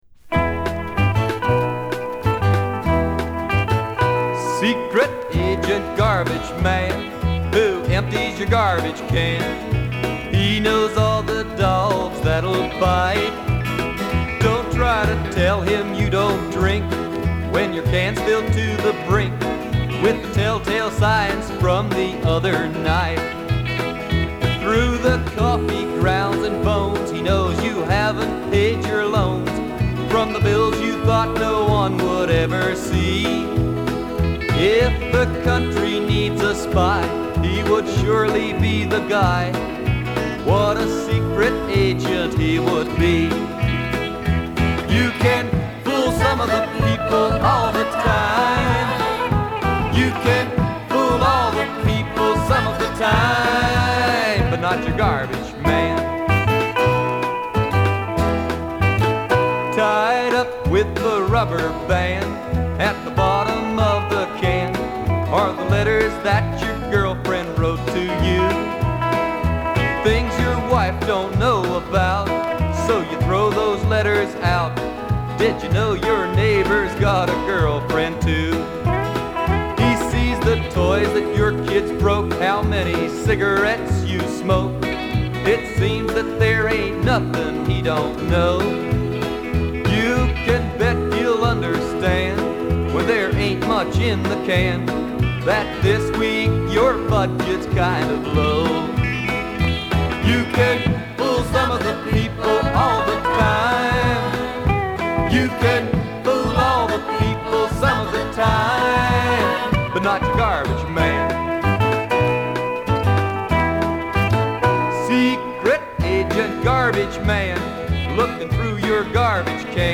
ユニークなノヴェルティ・カントリー・ロッカー